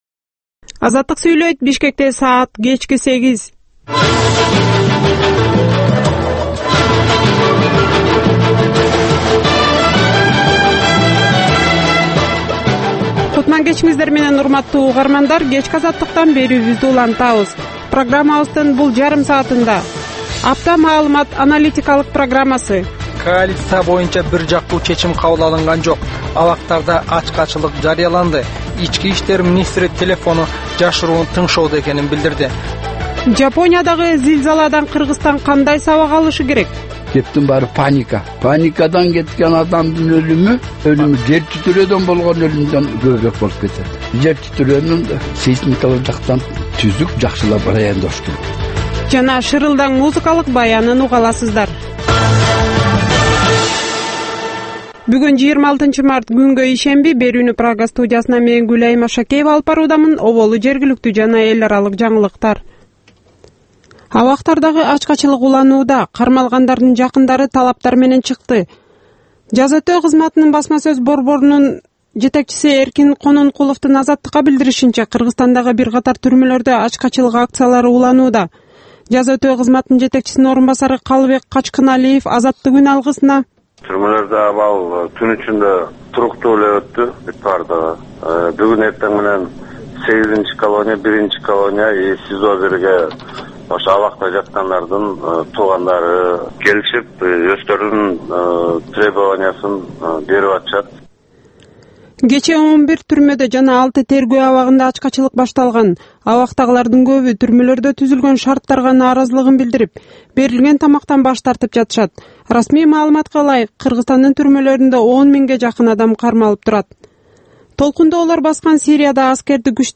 Кечки 8деги кабарлар